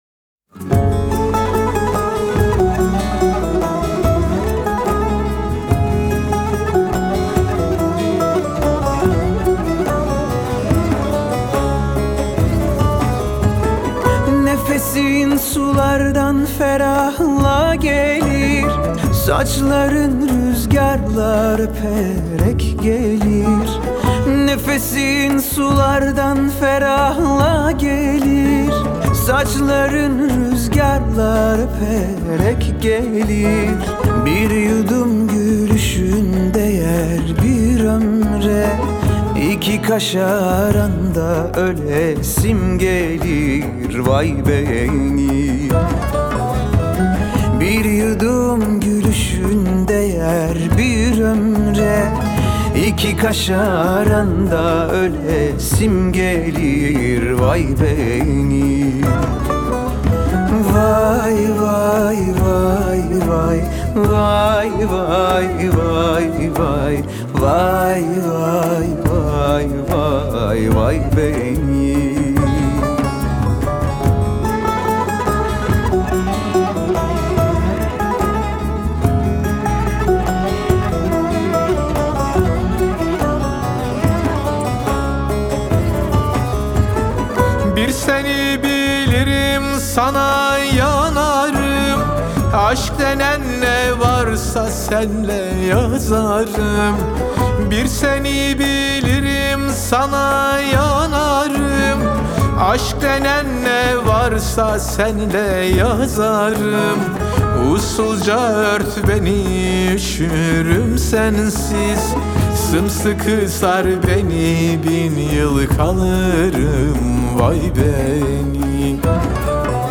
Трек размещён в разделе Турецкая музыка / Танцевальная.